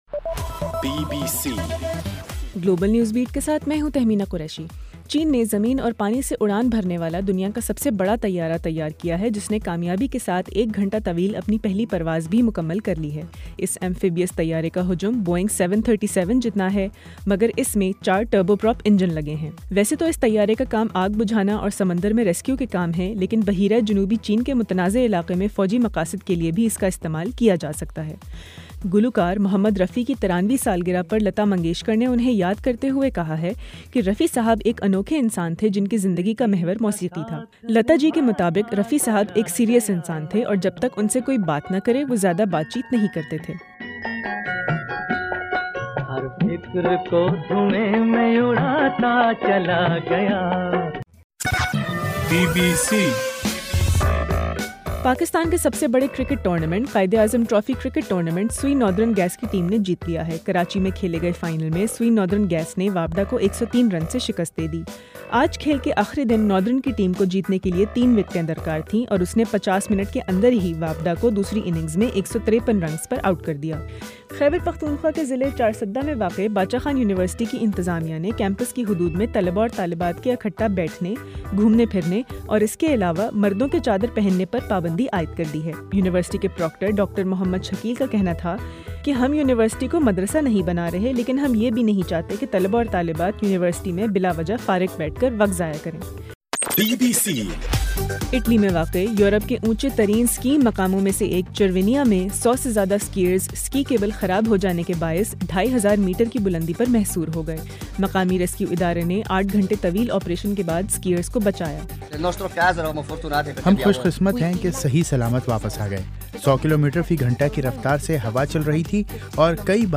گلوبل نیوز بیٹ بُلیٹن اُردو زبان میں رات 8 بجے سے صبح 1 بجے ہرگھنٹےکے بعد اپنا اور آواز ایفایم ریڈیو سٹیشن کے علاوہ ٹوئٹر، فیس بُک اور آڈیو بوم پر